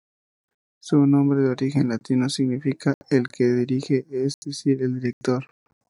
la‧ti‧no
/laˈtino/